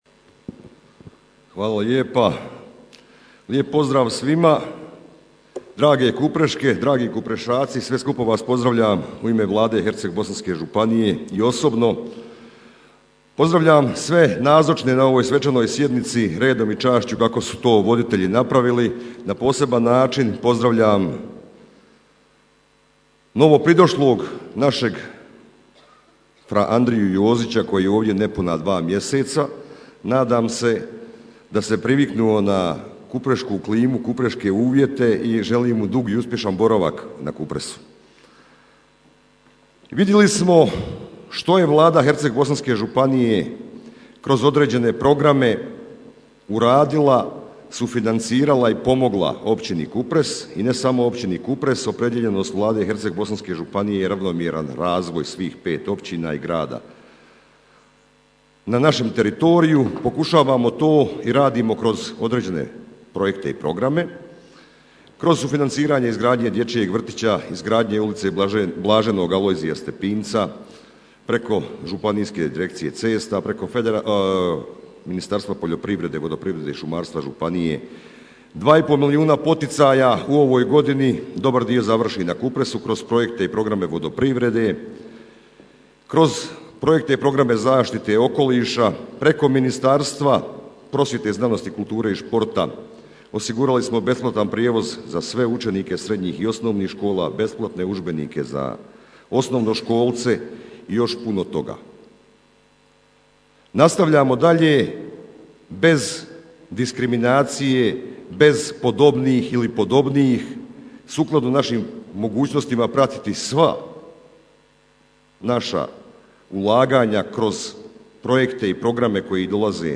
Prisutnima se obratio i premijer Vlade HBŽ g. Ivan Vukadin: